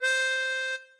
melodica_c1.ogg